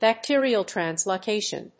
bacterial+translocation.mp3